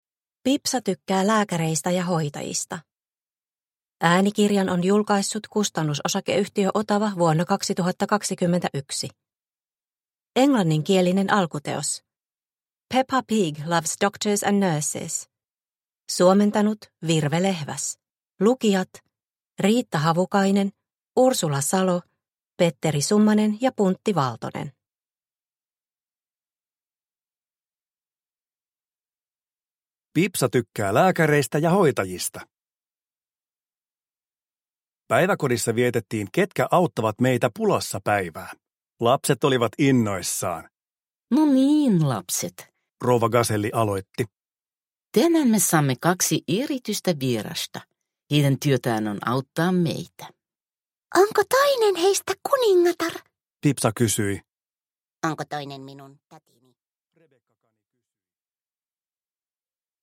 Pipsa tykkää lääkäreistä ja hoitajista – Ljudbok – Laddas ner